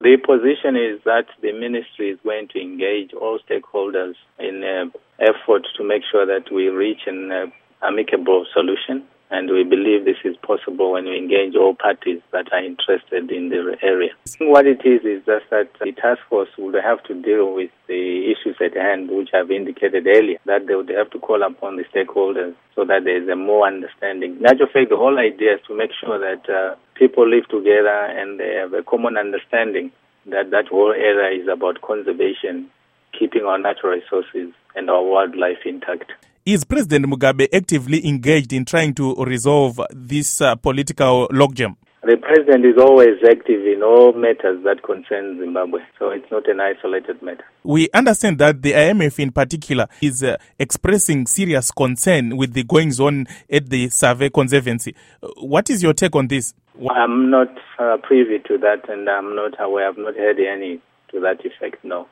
Interview With Francis Nhema